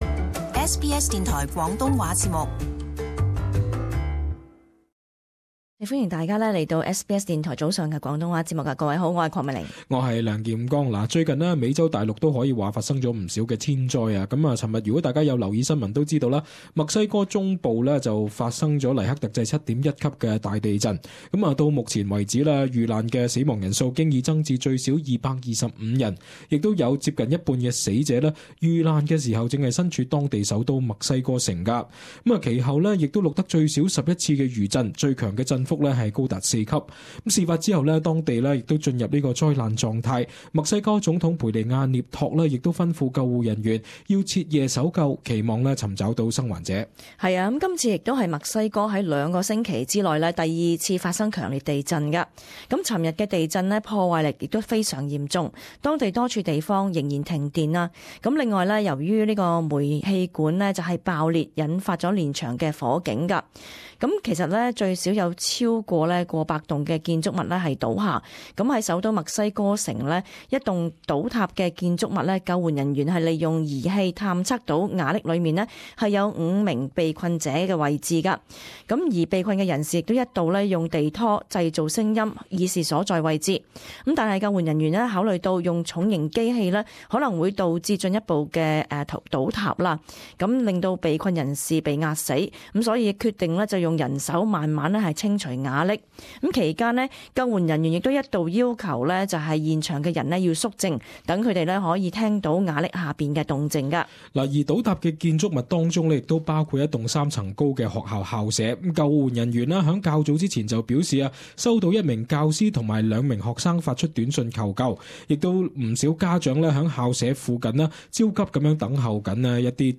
【時事報導】美洲大陸天災頻仍